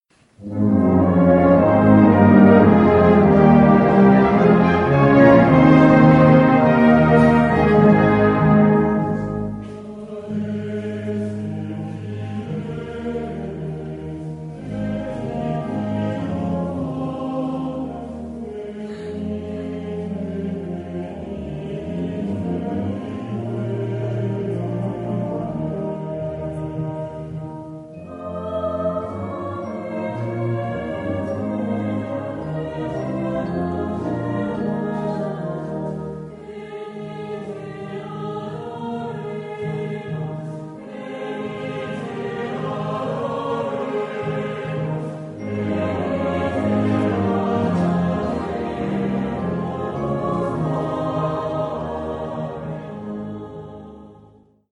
Drumset, Percussion 1, Percussion 2
Ensemble 6-7-8 voix Flex
Christmas Music / Musique de Noël